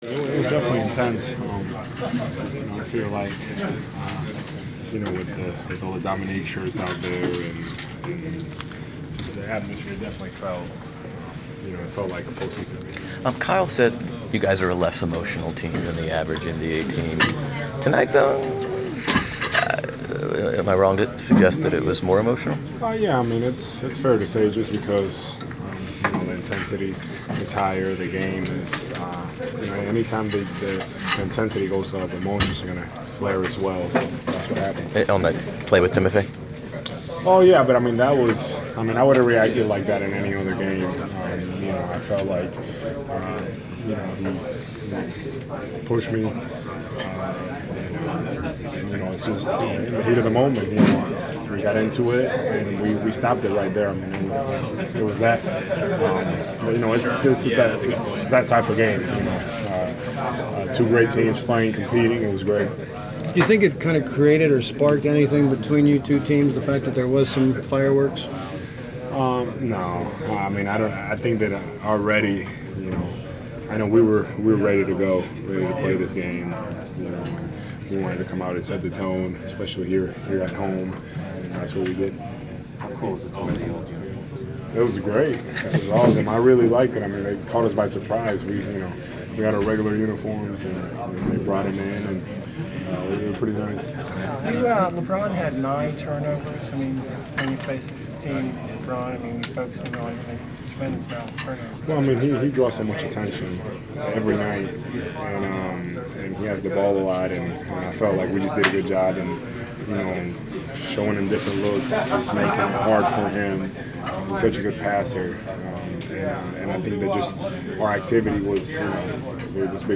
Inside the Inquirer: Postgame presser with Atlanta Hawk Al Horford (3/6/15)
We attended the postgame presser of Atlanta Hawks’ center Al Horford following his team’s 106-97 home win over the Cleveland Cavs on Mar. 6.